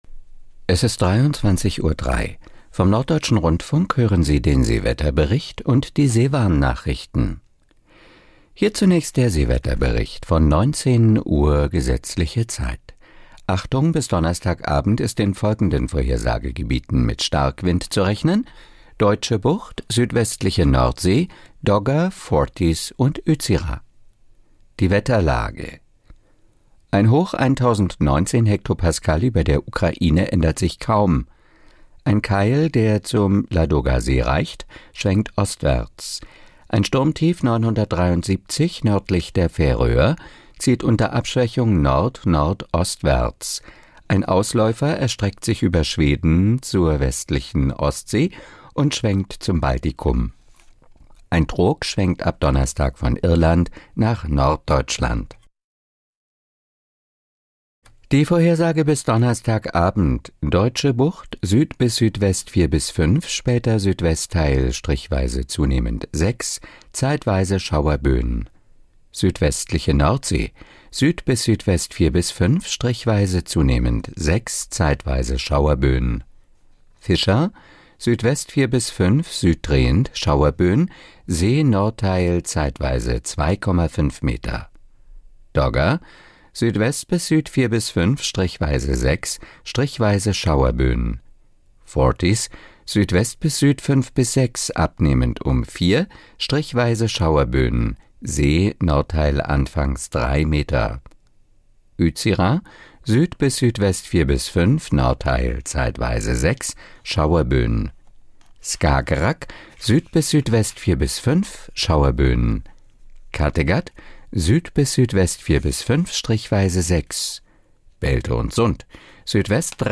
Nachrichten - 17.04.2025